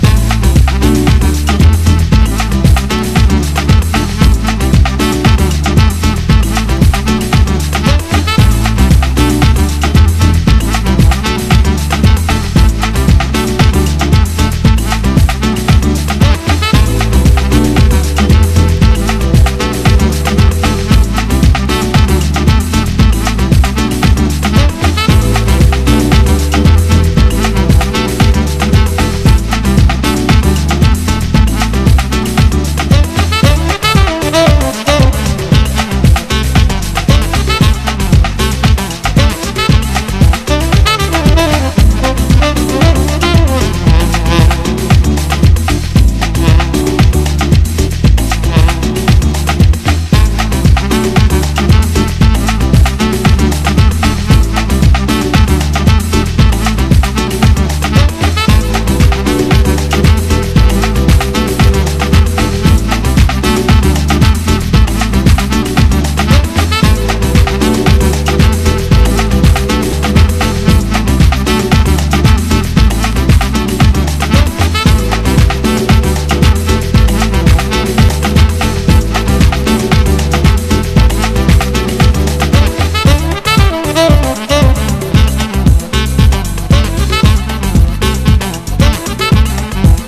NEO-ACO/GUITAR POP / SHOEGAZER
轟音ギター・ノイズが霧雨のように降り注ぐ
端正なヴォーカルと音渦にたっぷり浸れるシューゲイズ・サウンドを展開しています！